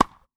Ball Hit Powerful Tennis.wav